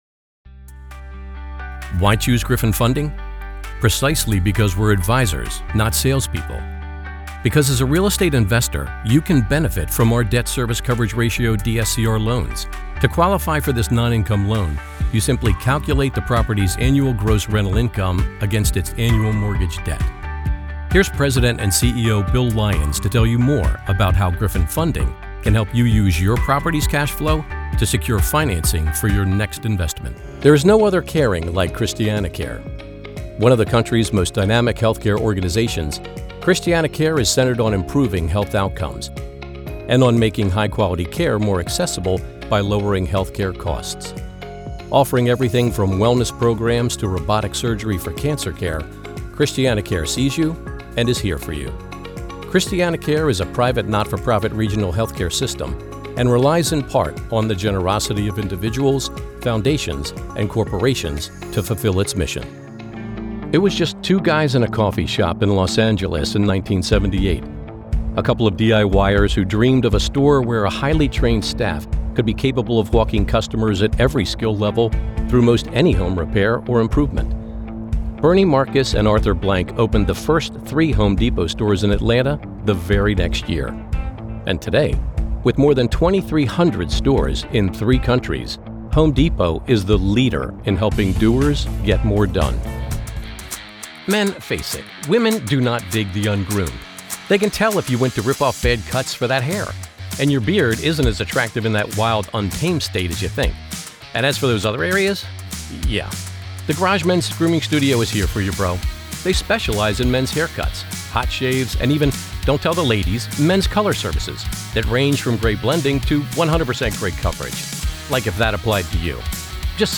Corporate Narration
• Sennheiser MKH416
• Professional Recording Booth
• Authentic, Approachable, Conversational and Friendly